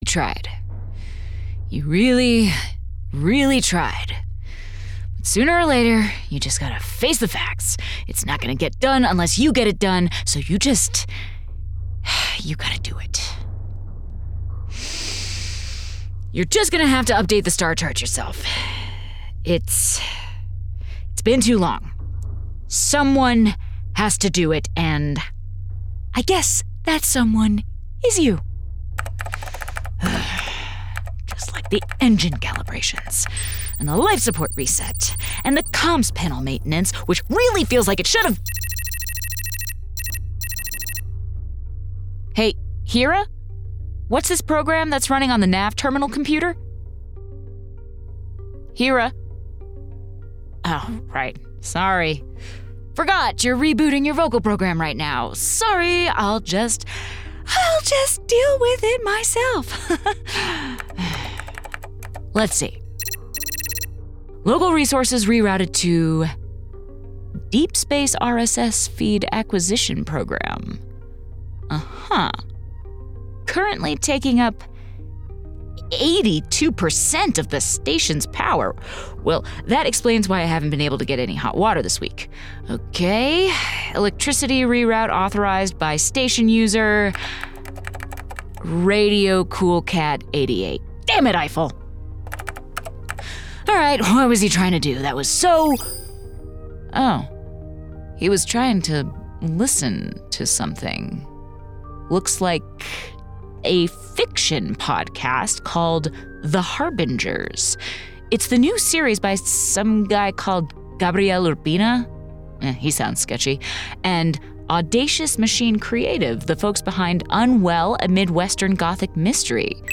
Wolf 359 is a radio drama in the tradition of Golden Age of Radio shows.